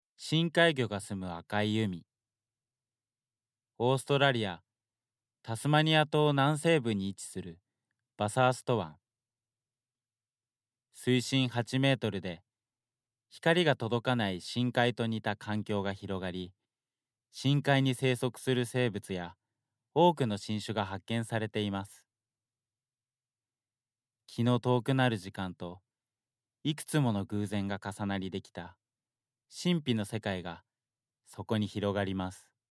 ボイスサンプル
自己PR